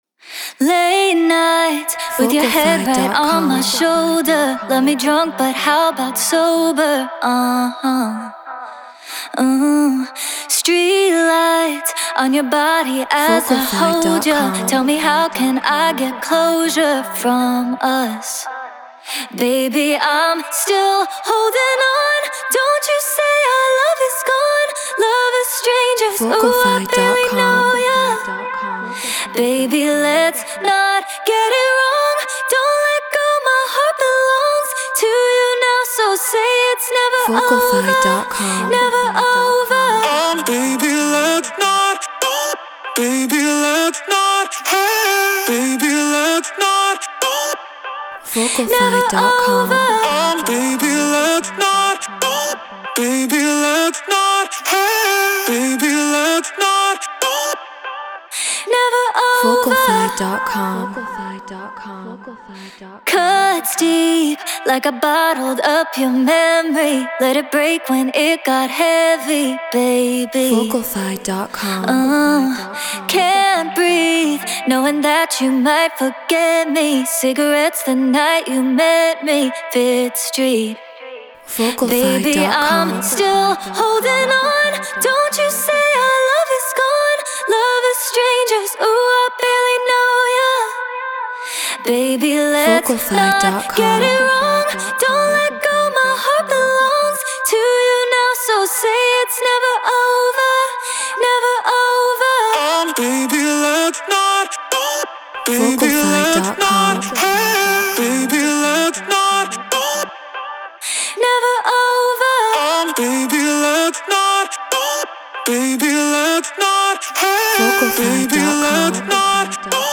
Pop 96 BPM Cmaj
Treated Room